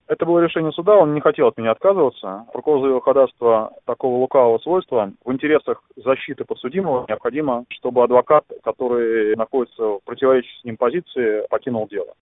Говорит адвокат